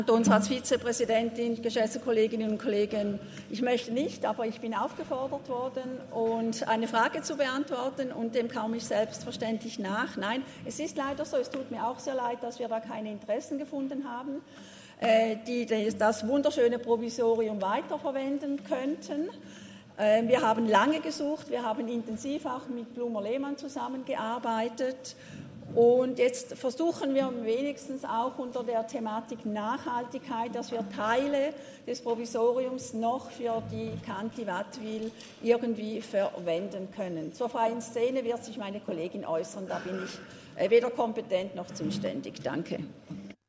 Session des Kantonsrates vom 18. bis 20. September 2023, Herbstsession
20.9.2023Wortmeldung